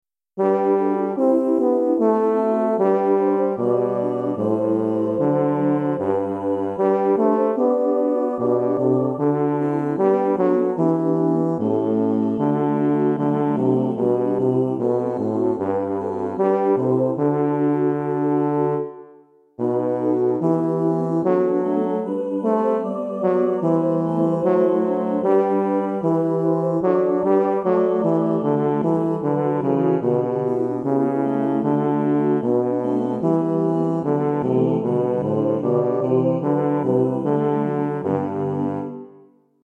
Nu vilar folk och länder bas
nu vilar folk_bas.mp3